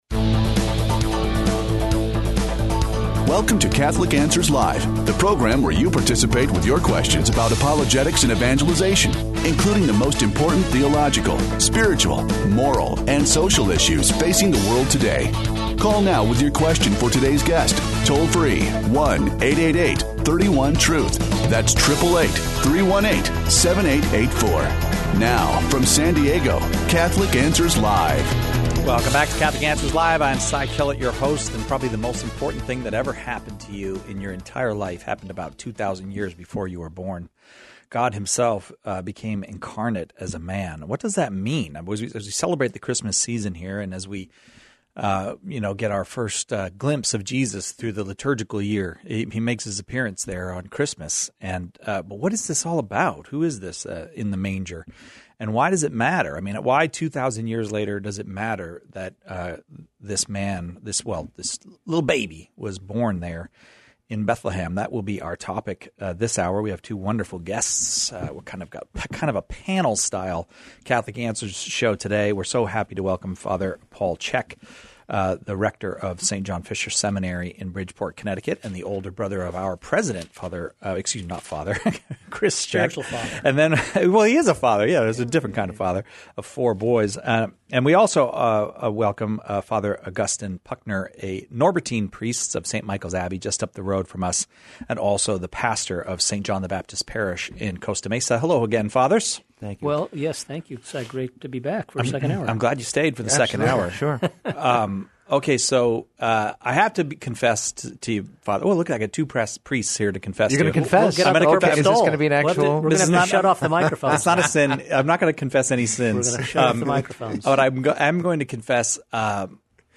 Our priestly panel discusses the effects of the incarnation of God in Jesus.